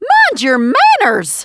piper_hurt_02.wav